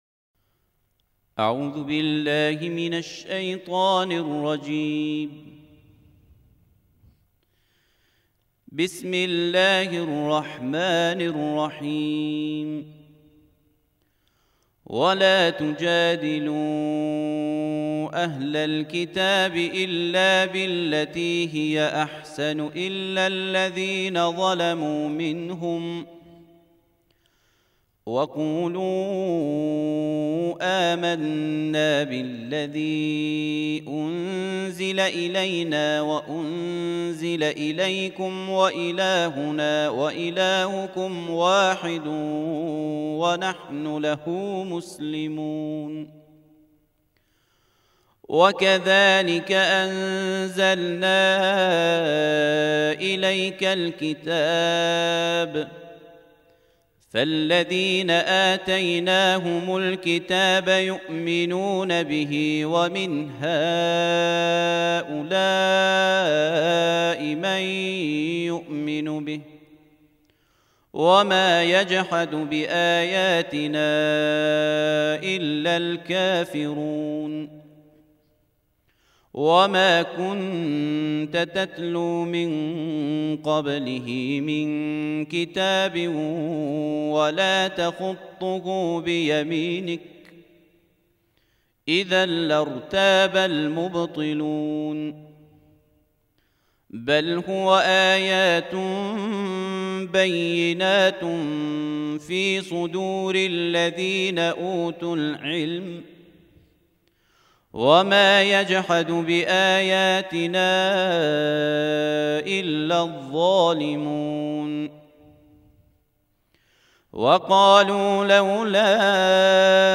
فیلم ‌| تلاوت ترتیل جزء بیست‌ویک قرآن کریم
در ادامه ترتیل جزء بیست‌ویکم قرآن کریم تقدیم مخاطبان گرامی ایکنا می‌شود.